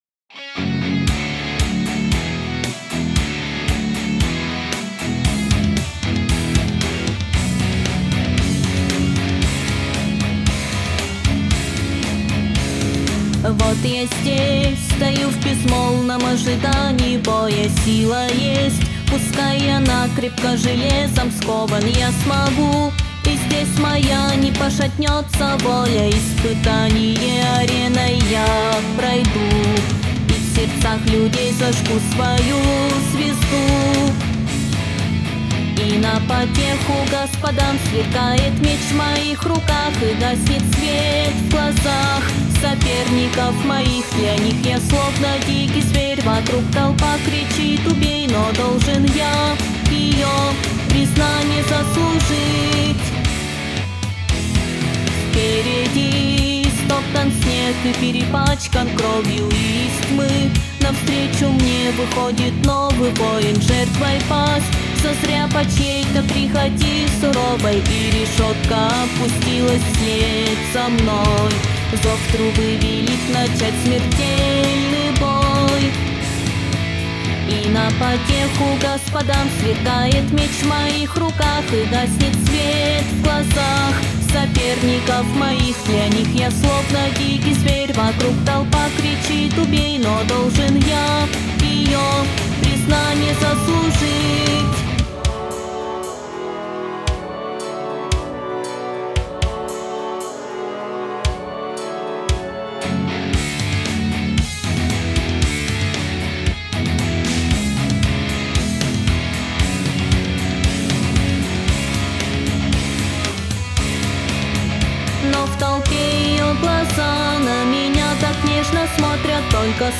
Если исключить, что гитара/барабаны играют не как настоящие, и взять чисто мелодию, то чем она хуже, вообще не понятно. Вложения Испытание ареной песня.mp3 Испытание ареной песня.mp3 3,5 MB · Просмотры: 525